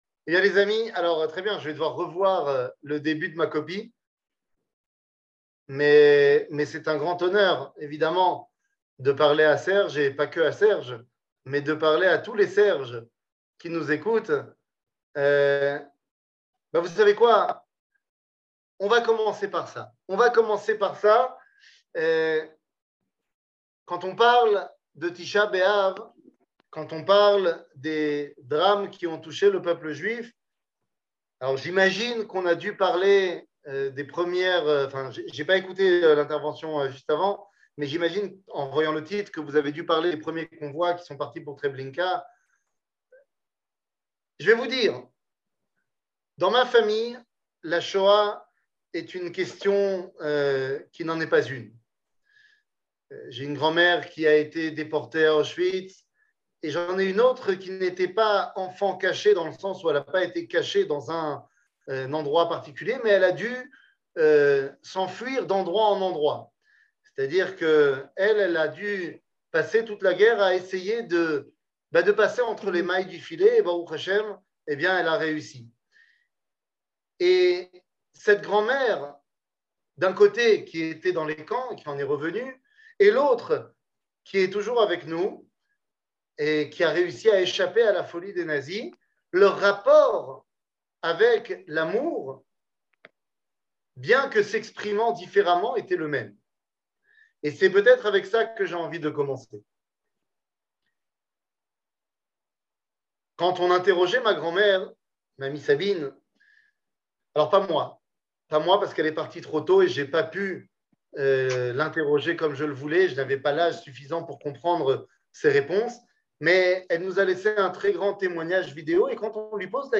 קטגוריה L'amour Eternel 01:01:04 L'amour Eternel שיעור מ 08 אוגוסט 2022 01H 01MIN הורדה בקובץ אודיו MP3